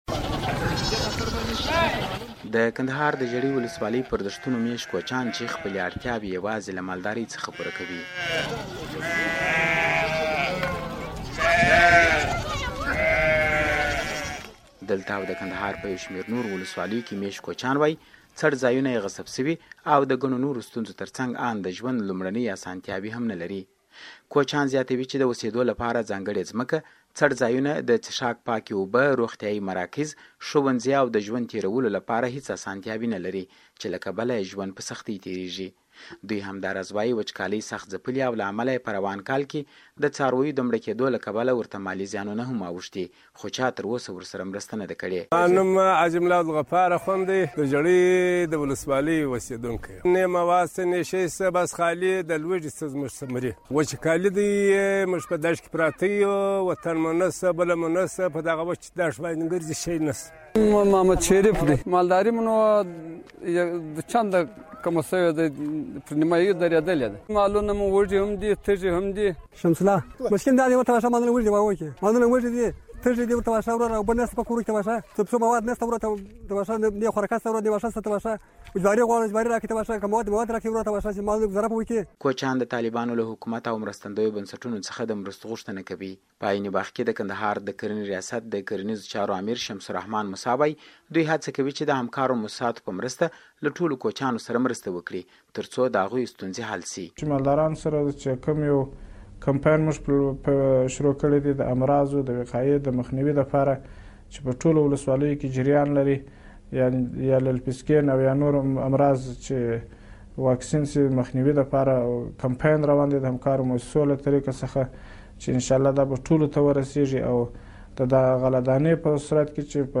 د کوچیانو راپور